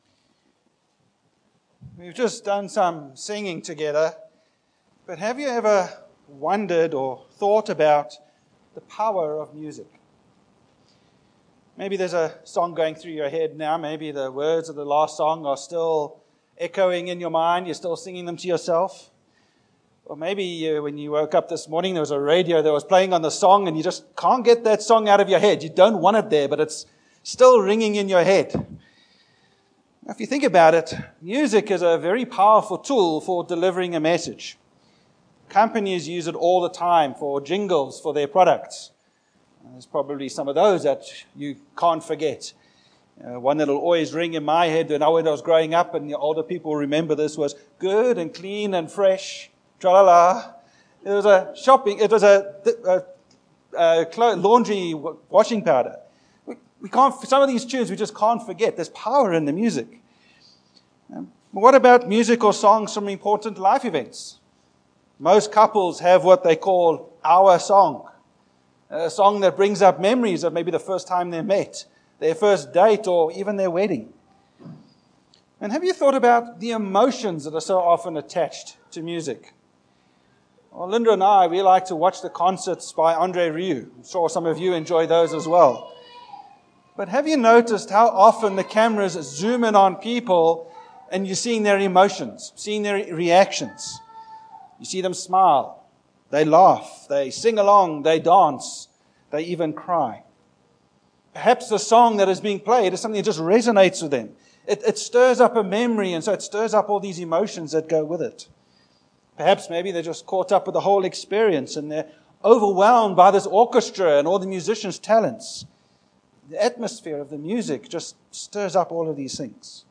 Living As The Community of God Passage: Colossians 3:16-17 Service Type: Morning